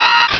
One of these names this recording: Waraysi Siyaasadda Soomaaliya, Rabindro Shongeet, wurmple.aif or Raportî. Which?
wurmple.aif